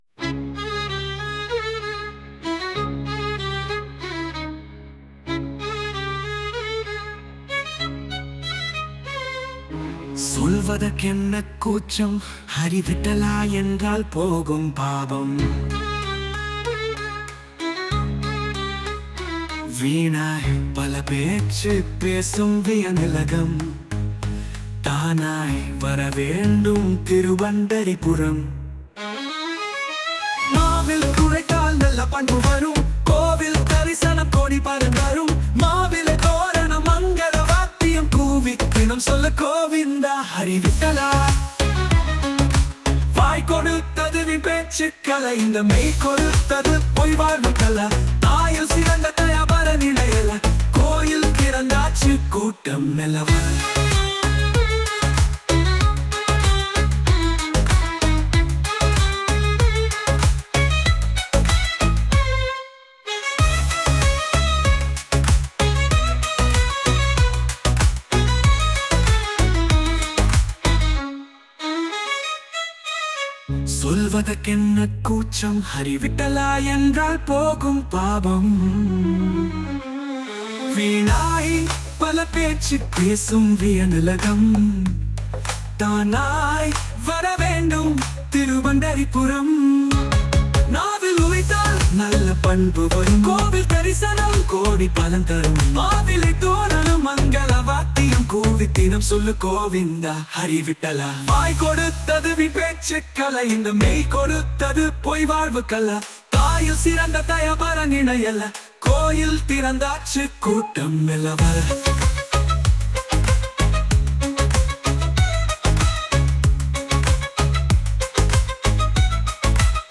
Posted in தமிழ் அபங்கங்கள், பாடல்கள்